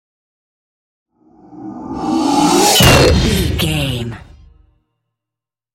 Dramatic horror metal hit large
Sound Effects
Atonal
heavy
intense
dark
aggressive